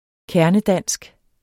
Udtale [ ˈkæɐ̯nəˈdanˀsg ] Betydninger helt igennem dansk